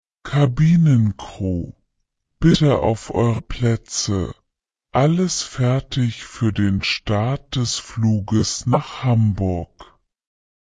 CrewSeatsTakeoff.ogg